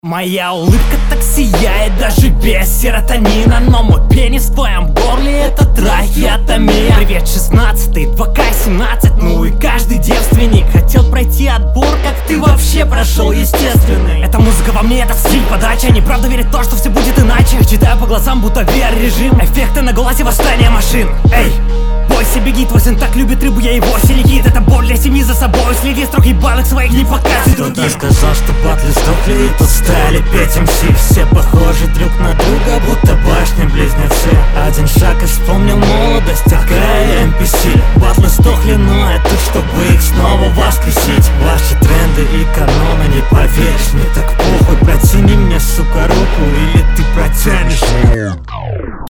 Грязные панчи не слишком удаются. Но подача довольно агрессивна, к концу правда начал невнятно считывать.